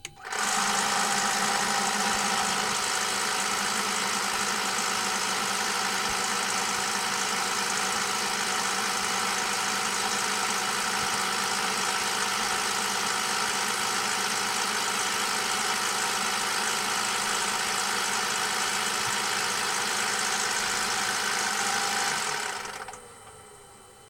Звуки минигана
Звук плавного вращения стволов без выстрелов